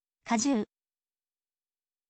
kajuu